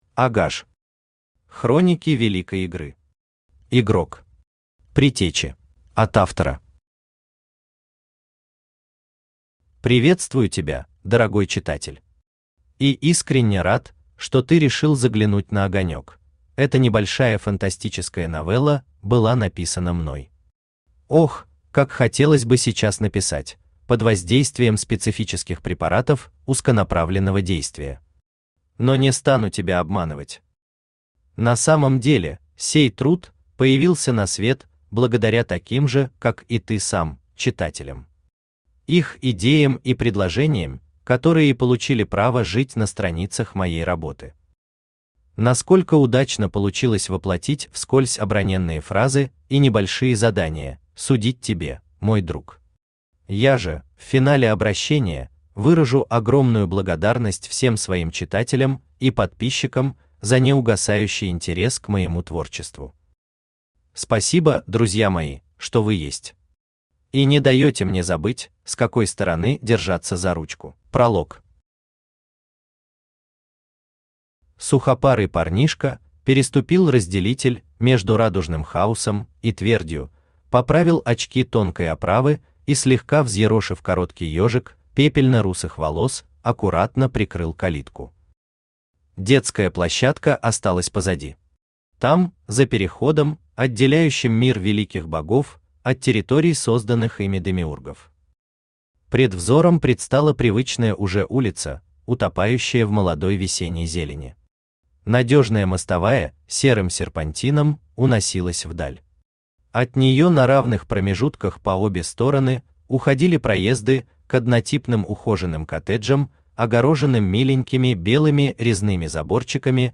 Аудиокнига Хроники Великой Игры. Игрок. Предтечи | Библиотека аудиокниг
Предтечи Автор А Р ГАЖ Читает аудиокнигу Авточтец ЛитРес.